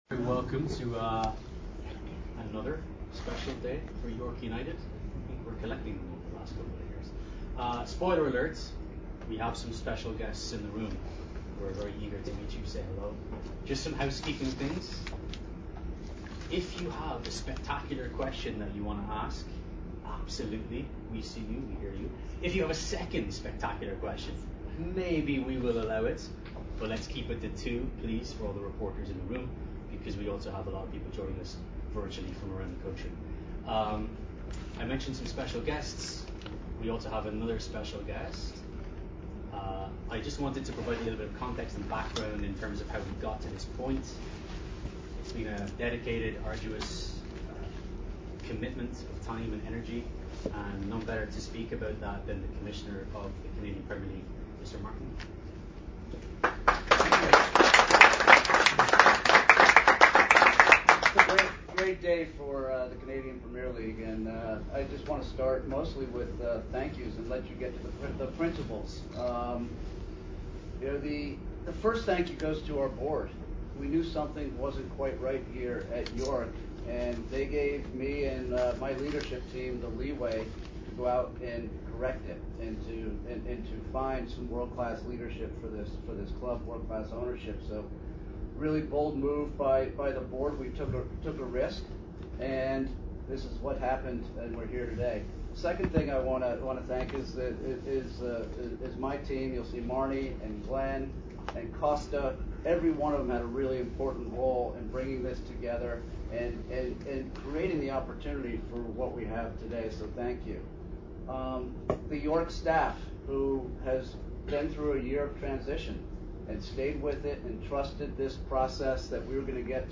press conference audio